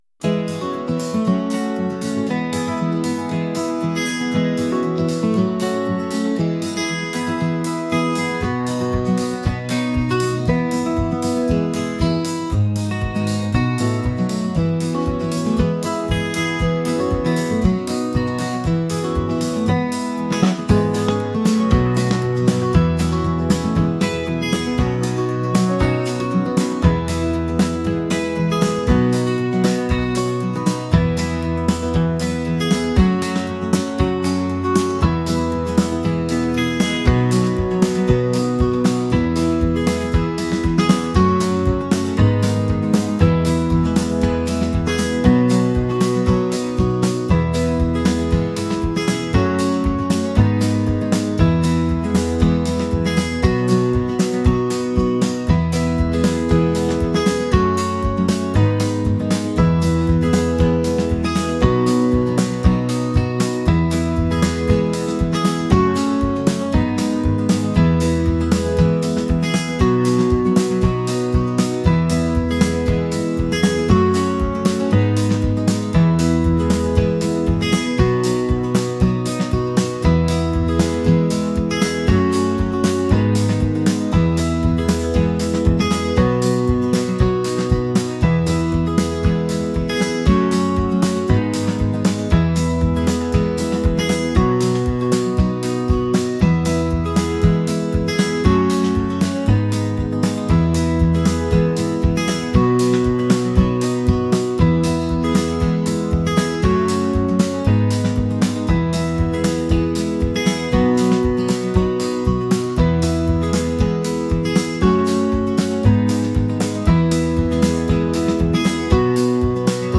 folk | upbeat | acoustic